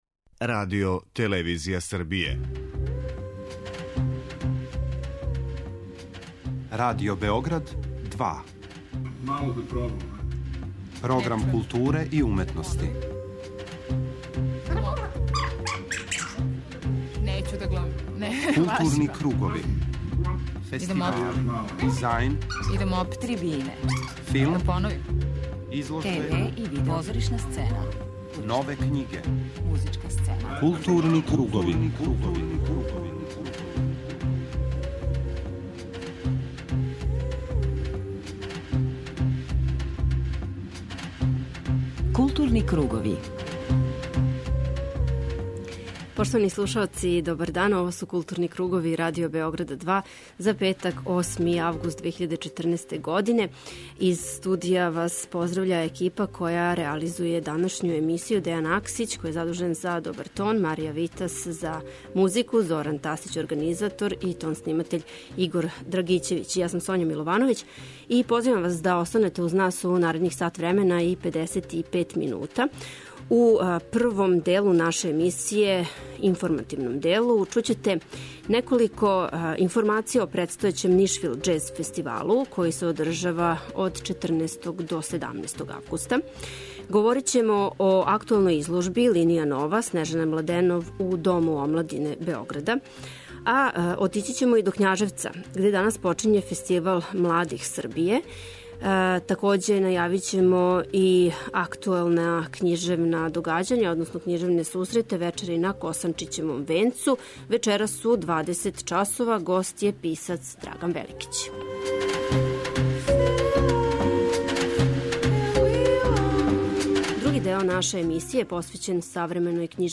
преузми : 52.97 MB Културни кругови Autor: Група аутора Централна културно-уметничка емисија Радио Београда 2.